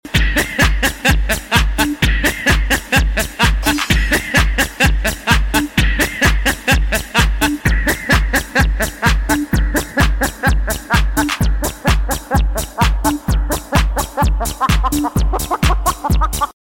HA HA HA HA HA sound effects free download